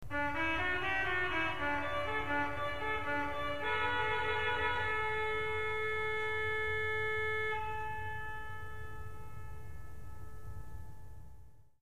Trąbka, Trompet, Trompette
Najważniejszy głos językowy w organach, typu kryjącego, posiada rezonatory w kształcie lejkowym.
trabka.mp3